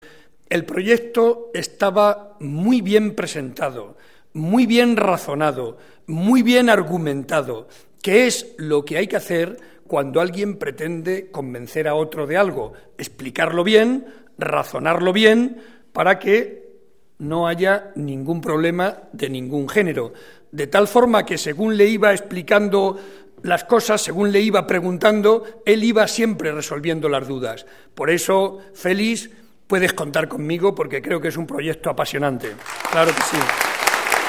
Ante más de 300 vecinos de la localidad, Barreda recordó que, en esta Legislatura, el Gobierno de Castilla-La Mancha ha invertido más de 15 millones de euros en este municipio conquense para mejorar sus instalaciones y prestaciones.